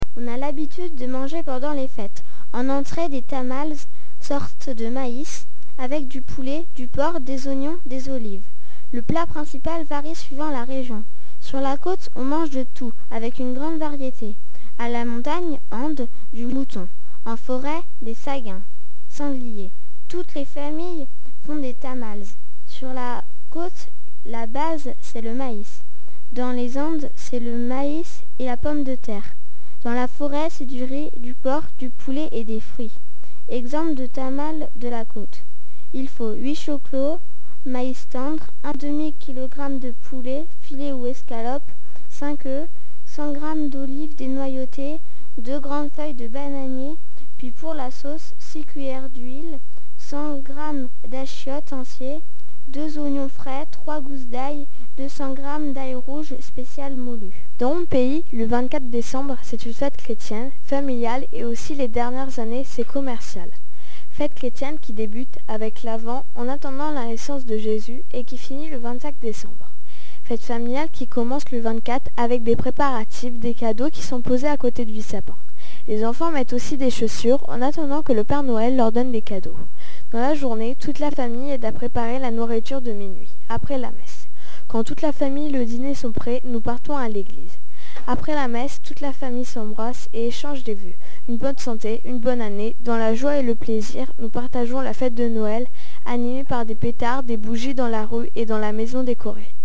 Texte lu